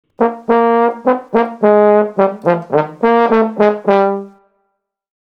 trombone_tuba_trompet_hoorn_4
trombone_tuba_trompet_hoorn_4.mp3